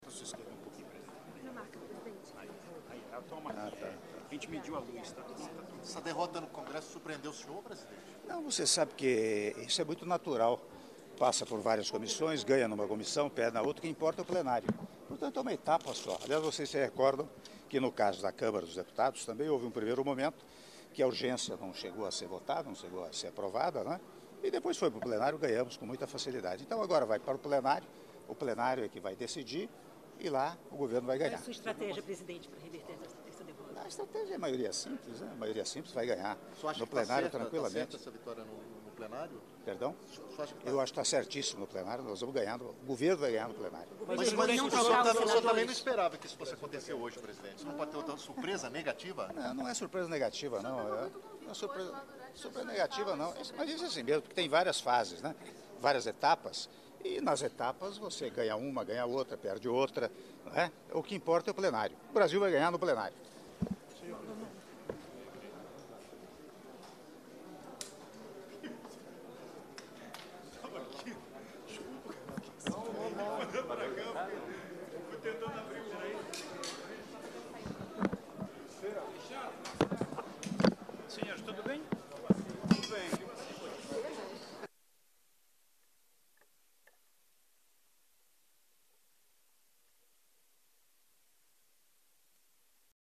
Áudio da entrevista coletiva concedida pelo Presidente da República, Michel Temer, na saída do Hotel Ritz-Carlton - (01min33s) - Moscou/Rússia